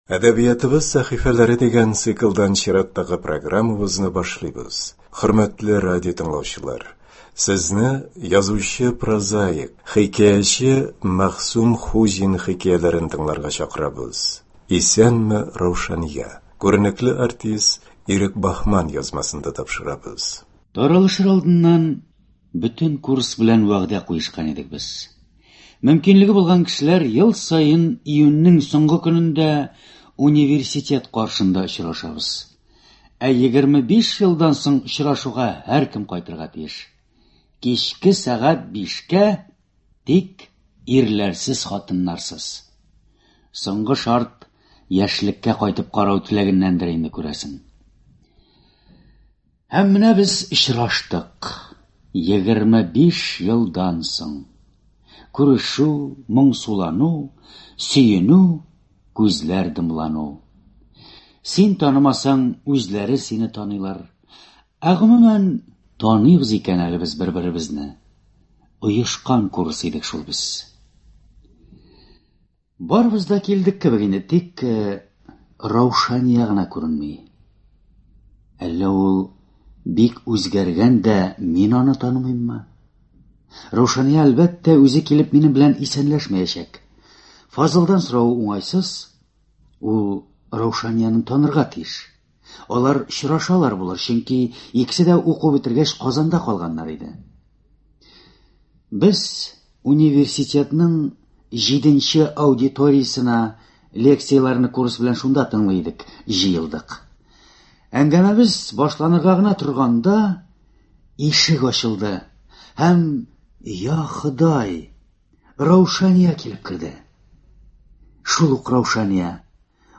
Радиокомпозиия.